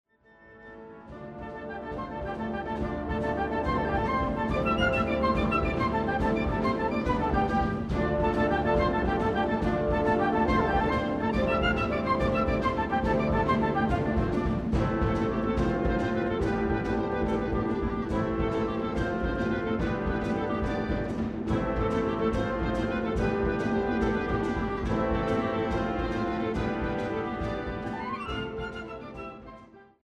Gattung: Suite
Besetzung: Blasorchester